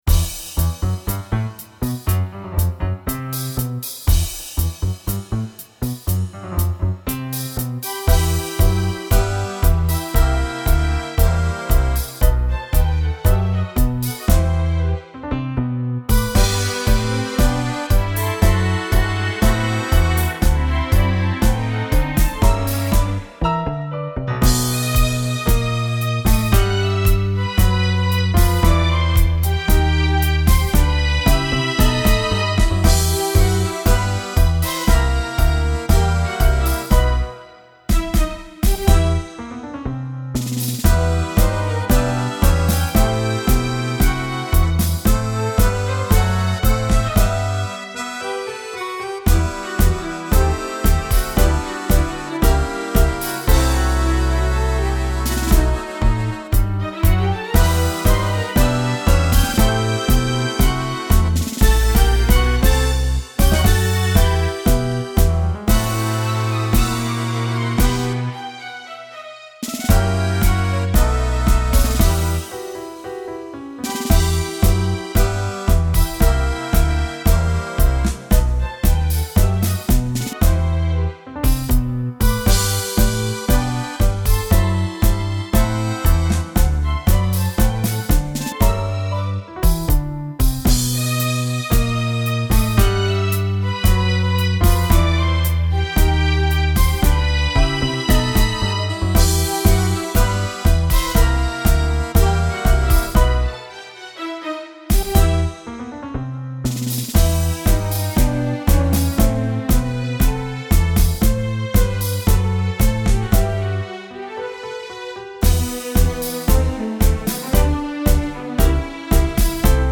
(solo base)